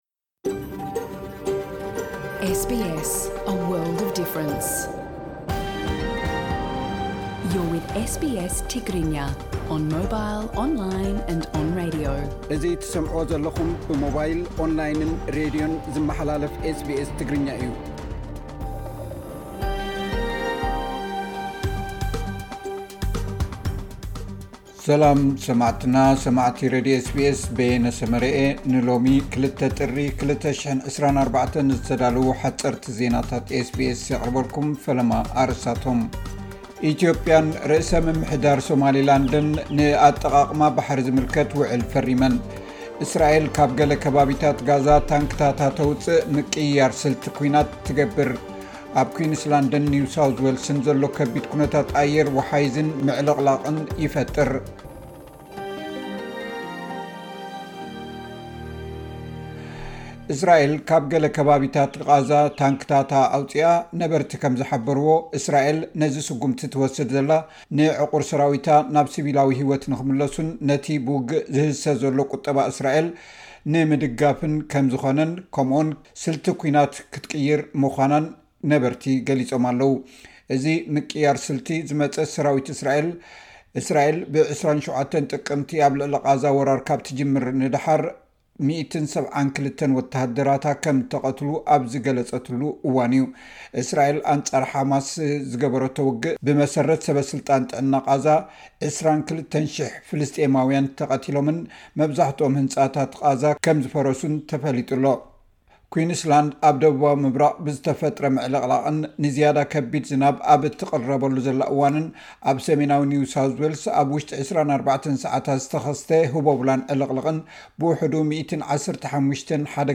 ስምምዕ ባሕሪ ኢትዮያን ሶማሊላንድን፡ ሓጸርቲ ዜናታት ኤስ ቢ ኤስ ትግርኛ (02 ጥሪ 2024)